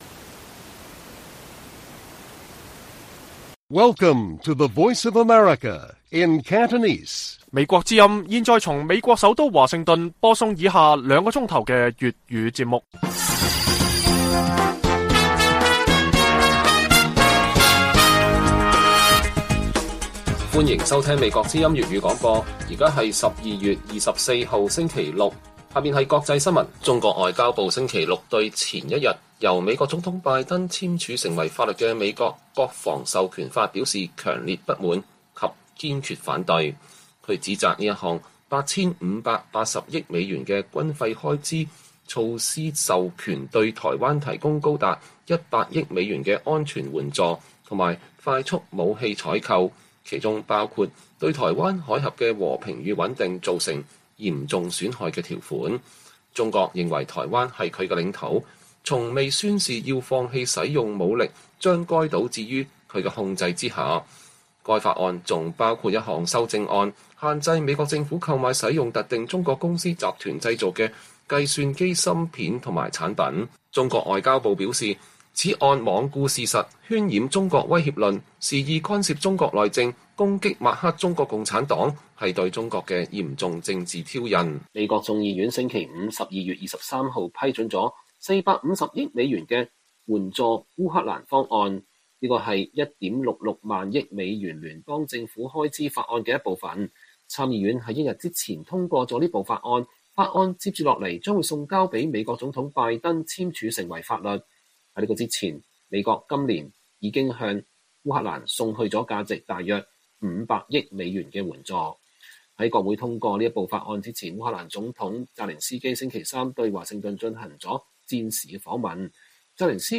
粵語新聞 晚上9-10點 : 台灣新民調：對中國威脅有共識 7成人認應延長兵役為一年